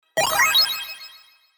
ピコピコとした8ビットサウンドが高鳴り、突如として爆風音が鳴り響く。
通知音としてのインパクトは抜群。